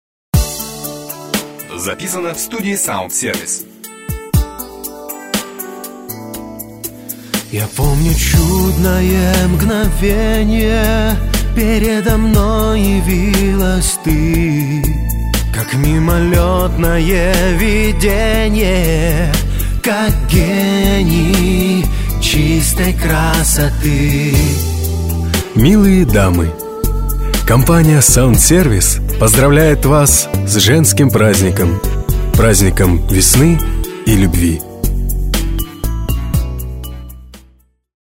Мужчины студии звукозаписи «СаундСервис» разработали и записали необычное поздравление для прекрасных дам в честь праздника Весны и любви!